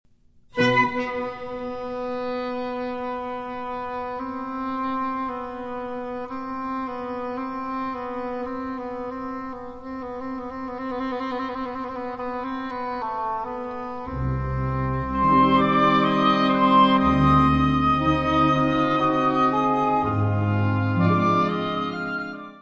Kategorie Blasorchester/HaFaBra
Unterkategorie Suite
Mlt: Bells / Xylo / Vibes / Chimes